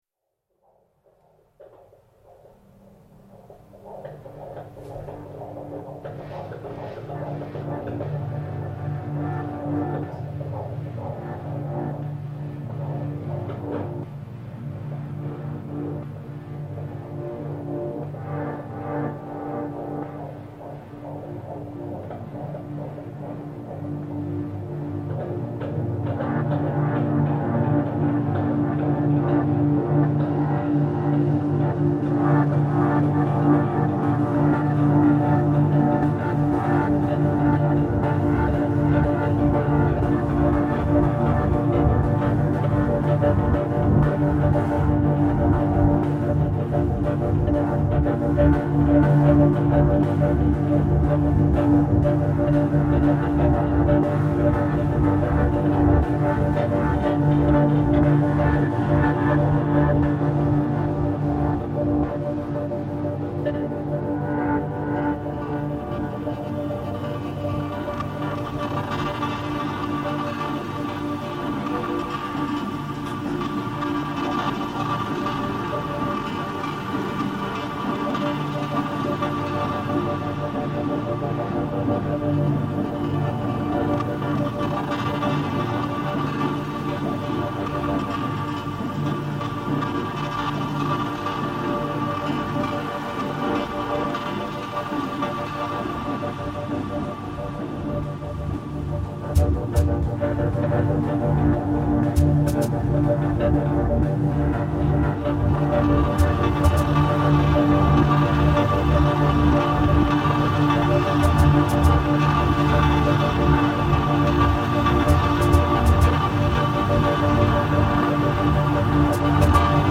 Oxford heartbeat reimagined